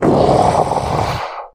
spawners_mobs_mummy_death.1.ogg